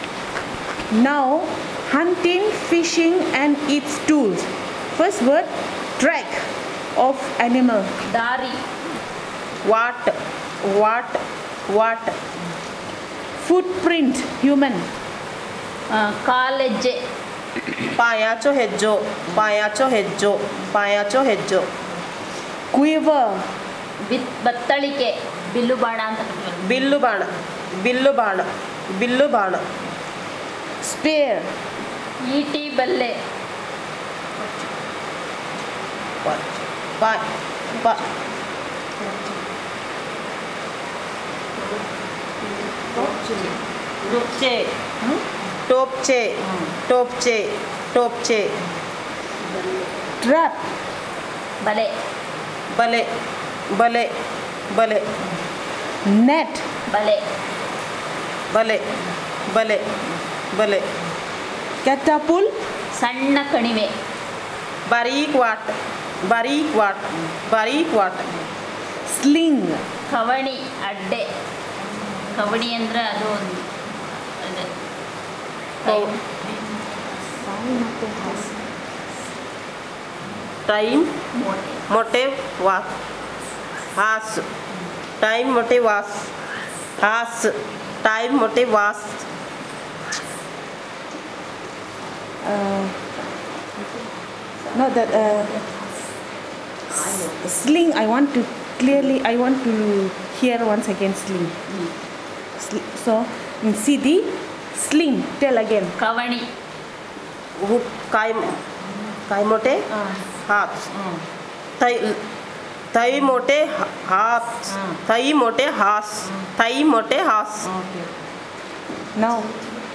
Elicitation of words about hunting fishing and related